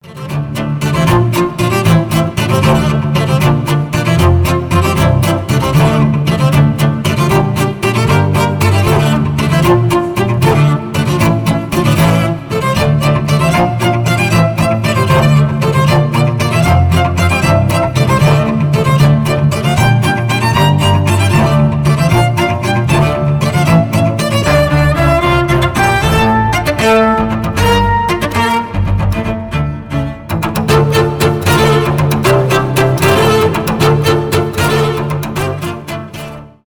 инструментальные , рок , без слов
эпичные , neoclassical , виолончель